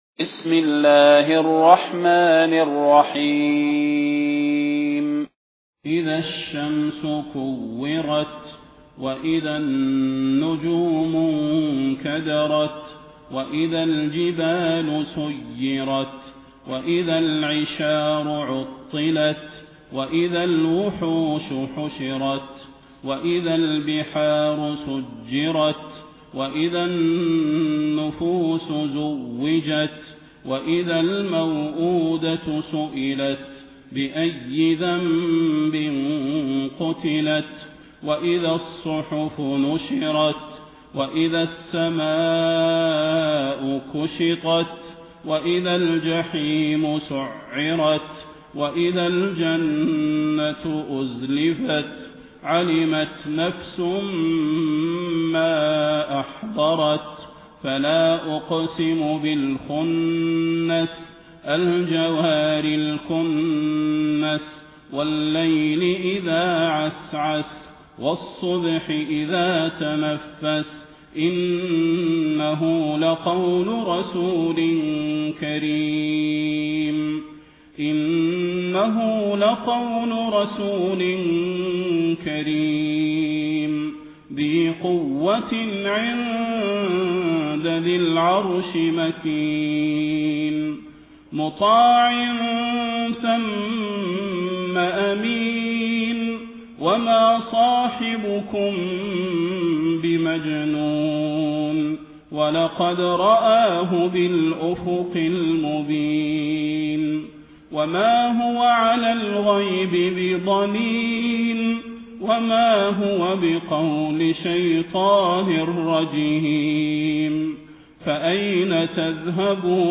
المكان: المسجد النبوي الشيخ: فضيلة الشيخ د. صلاح بن محمد البدير فضيلة الشيخ د. صلاح بن محمد البدير التكوير